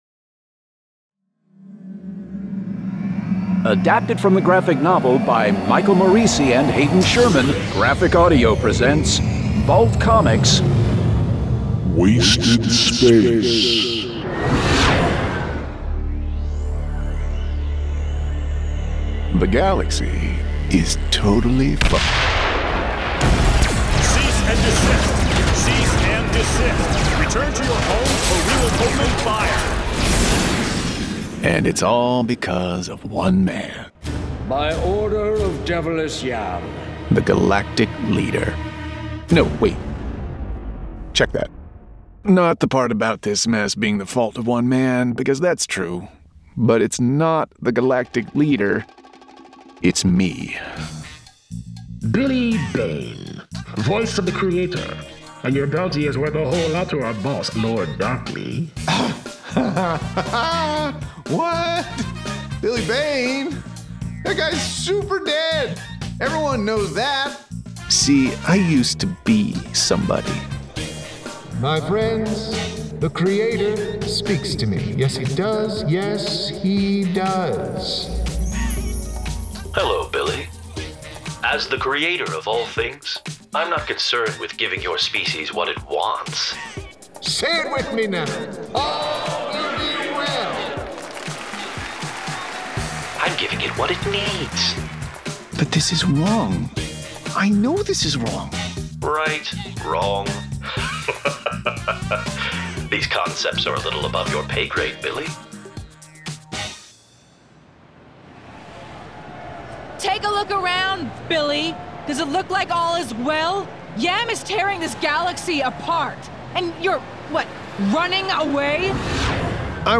Full Cast. Cinematic Music. Sound Effects.